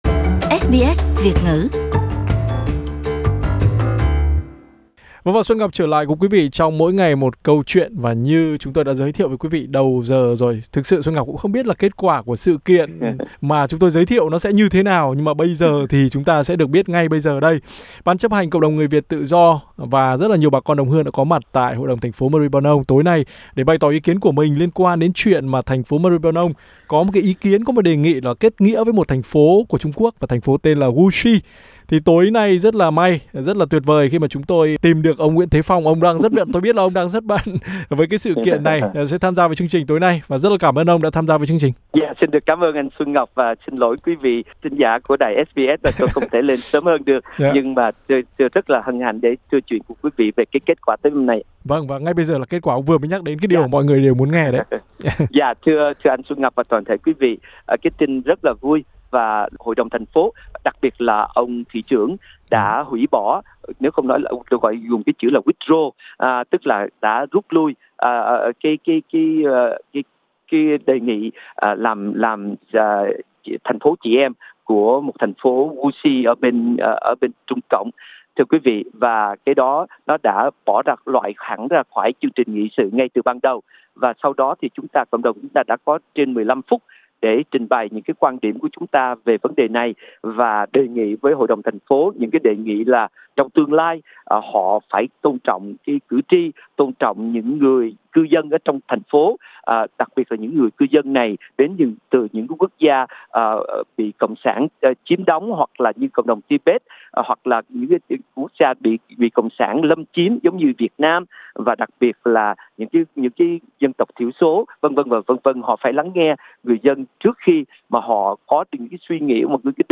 Trả lời phỏng vấn của SBS